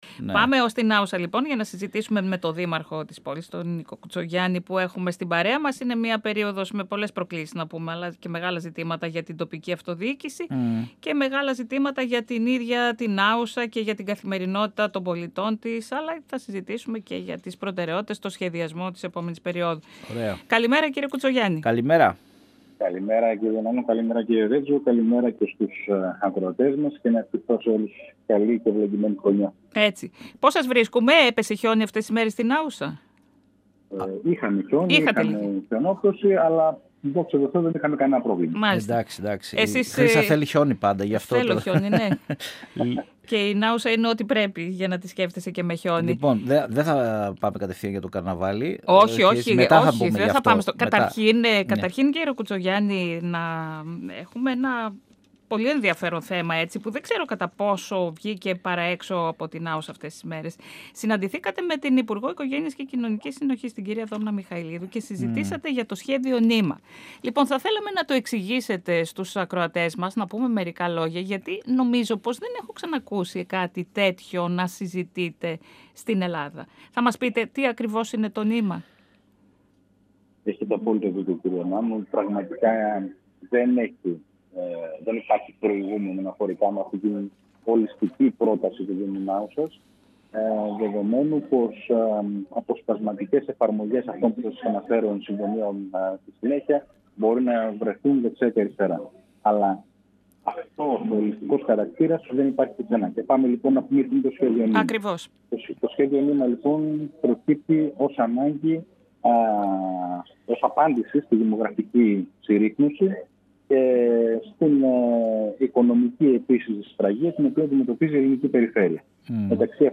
Για σημαντικά ζητήματα που αφορούν την πόλη του, μίλησε στην εκπομπή «Τα πιο ωραία πρωινά» του 102fm, ο δήμαρχος Νάουσας Νίκος Κουτσογιάννης.